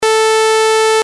pic 3-8: typical sawtooth, square and noise waveform with corresponding harmonics
saw440.mp3